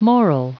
Prononciation du mot moral en anglais (fichier audio)
Prononciation du mot : moral